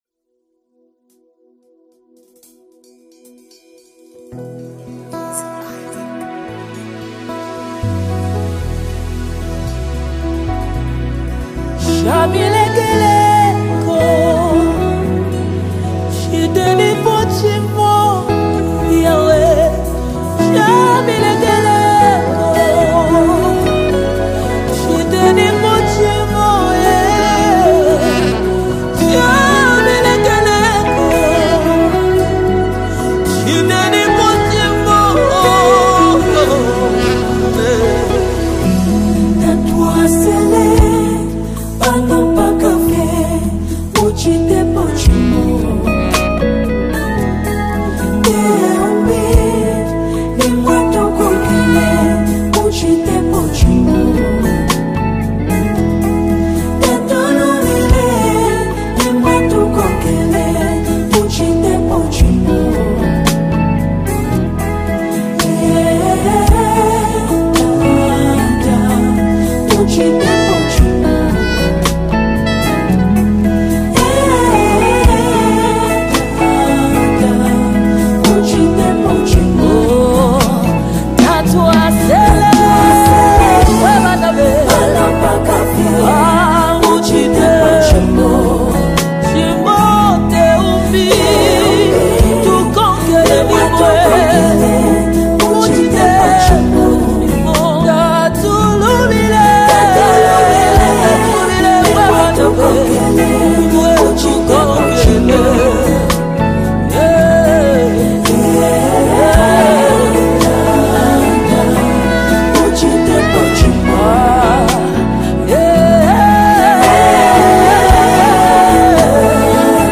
A deeply anointed song that encourages trust in God
📅 Category: Zambian Deep Worship Song 2025